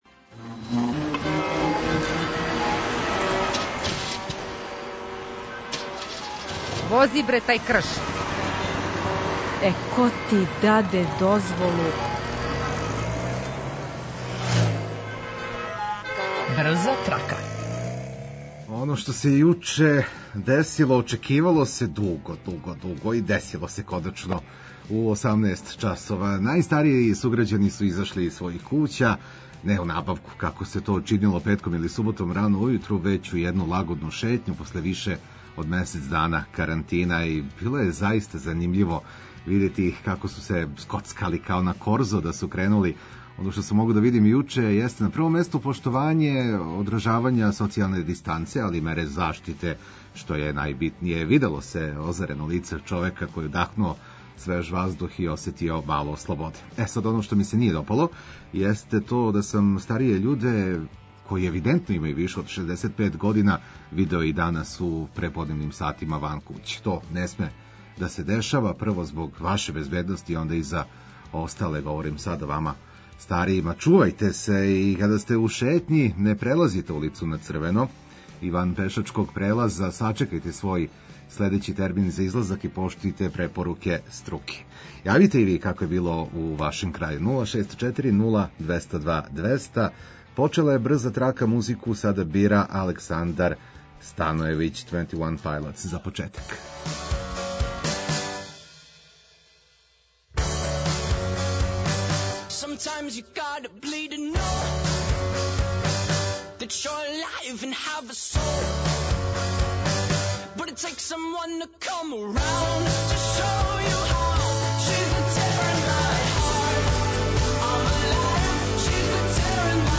Ту је и одлична музика која ће вам олакшати сваки минут, било где да се налазите.